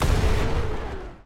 WULA_RW_Rocket_Shootingsound.wav